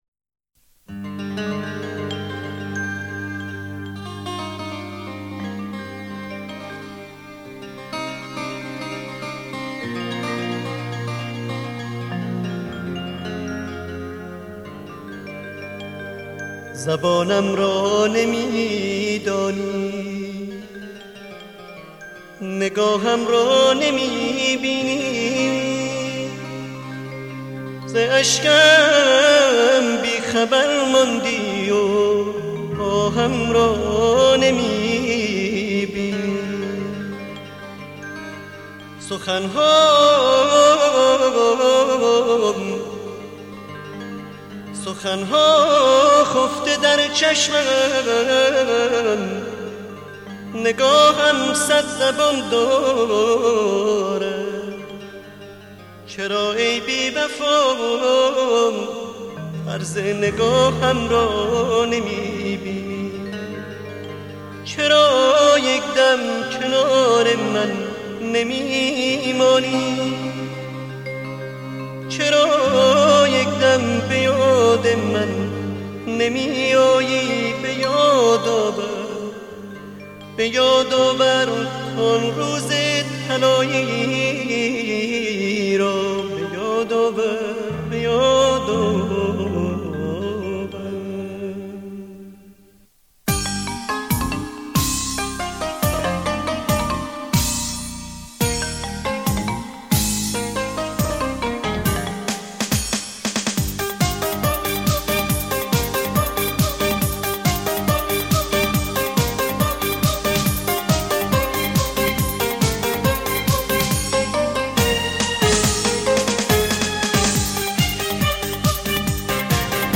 دسته : موسیقی ملل